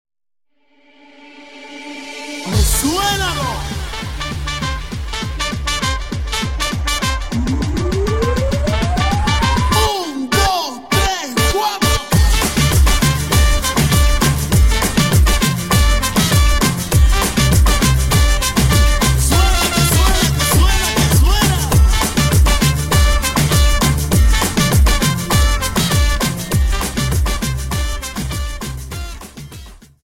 Dance: Samba 51